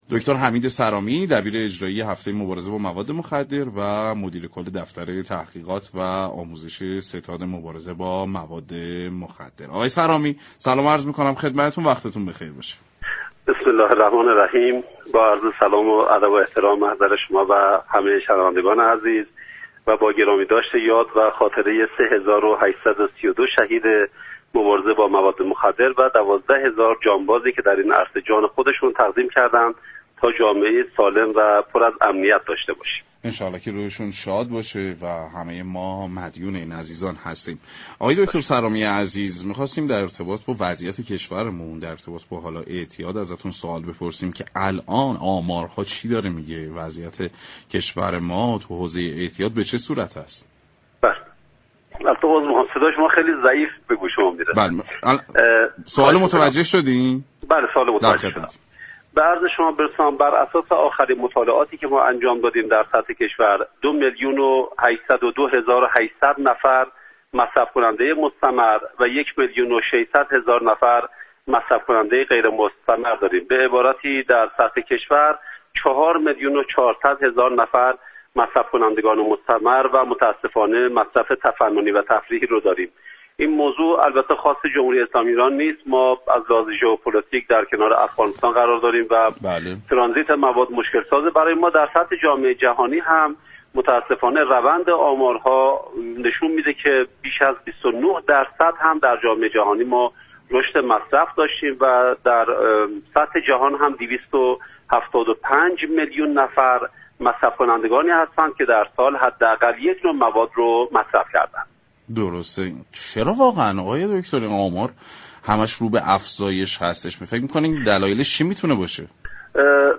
به گزارش پایگاه اطلاع رسانی رادوی تهران، حمید صرامی مدیركل دفتر تحقیقات و آموزش ستاد مبارزه با مواد مخدر در گفتگو با سعادت آباد رادیو تهران، گفت: بر اساس آخرین مطالعاتی كه در سطح كشور انجام شده دو میلیون 802 هزار و 800 نفر مصرف كننده مستمر و یك میلیون 600 هزار نفر مصرف كننده غیر مستمر داریم به عبارتی در سطح كشور چهار میلیون و 400 هزار نفر مصرف كننده مستمر و مصرف كننده تفننی و تفریحی داریم.